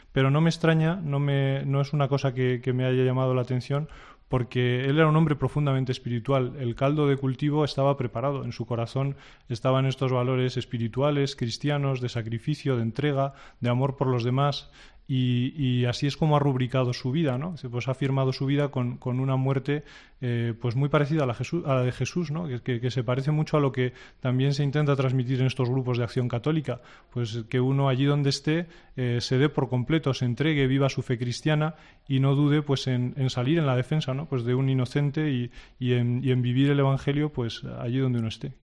Diego Padrón , presidente de la Conferencia Episcopal Venezolana: “Venimos a ratificar que hay una sola Iglesia y que estamos en plena comunión con el Santo Padre”